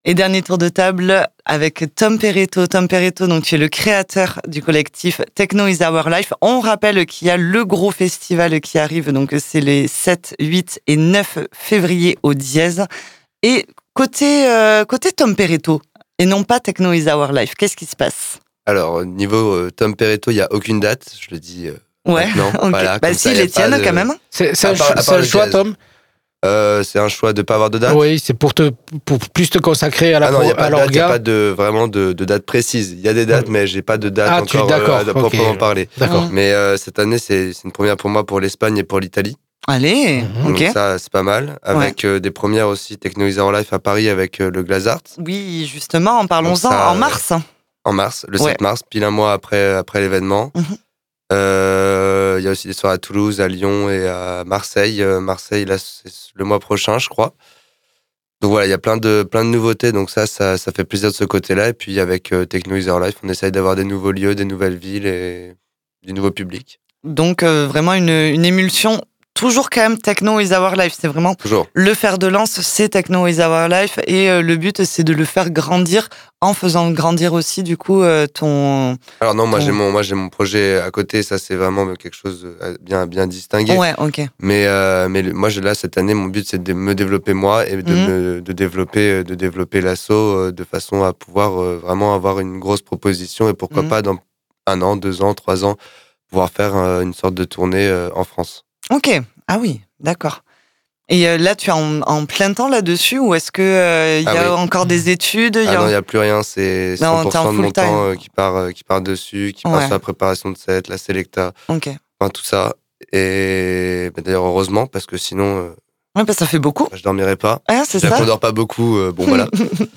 LE MIX DE NOS GUEST